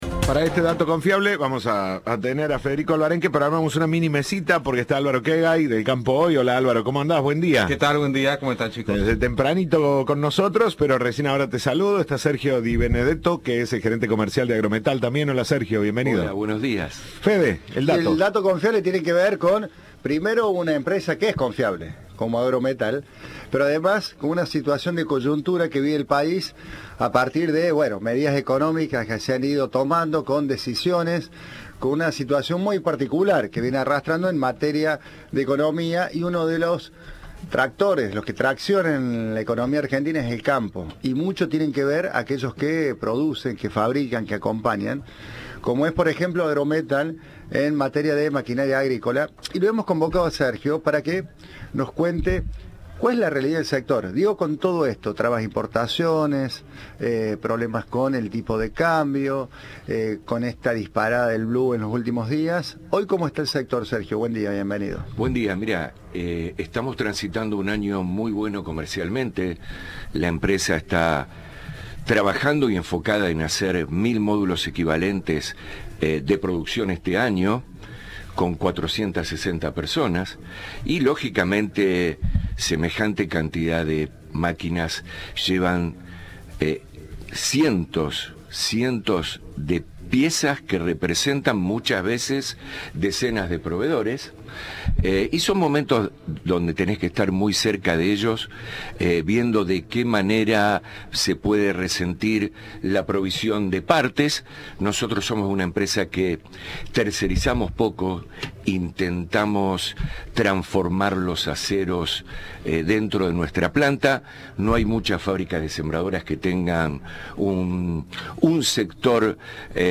Entrevista de Siempre Juntos.